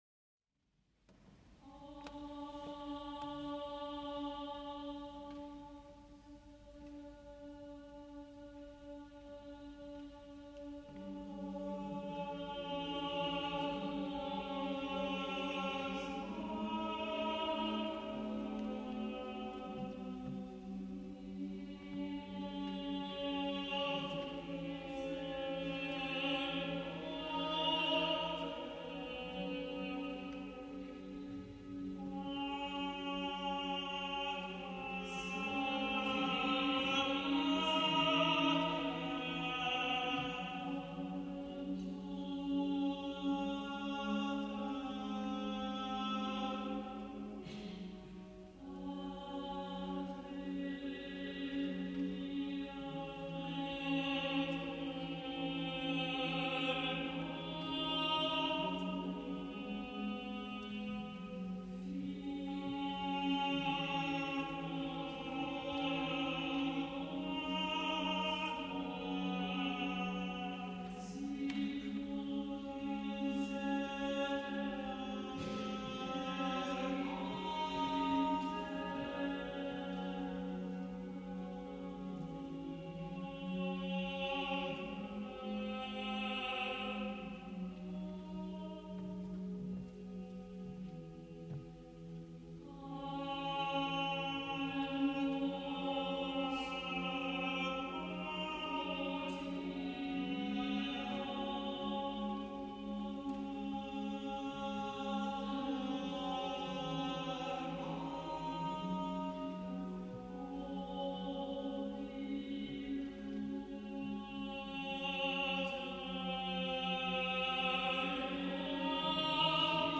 for 12-part mixed choir
for 12 part vocal ensemble (SSS-AAA-TTT-BBB)